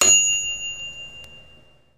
Звуки микроволновой печи
Звук Дзинь механической микроволновки